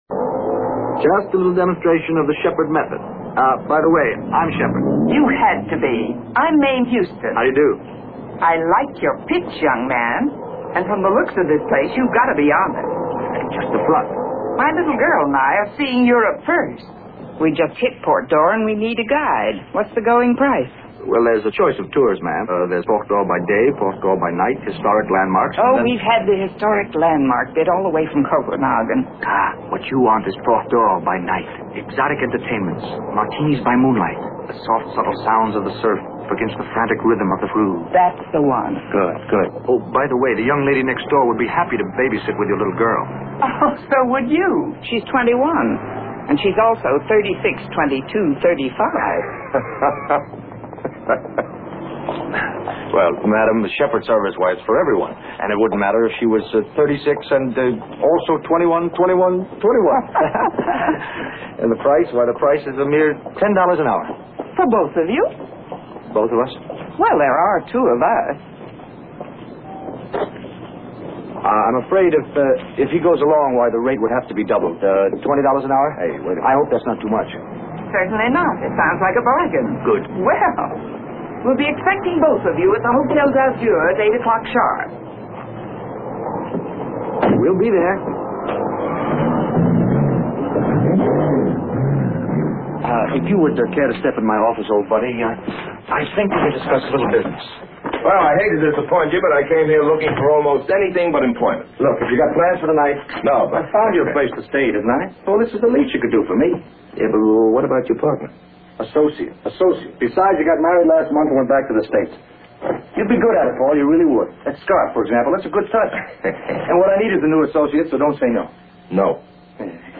(Bobby does not sing in the program, but shows his great athletic ability in a high speed bicycle chase.)